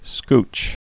(skch)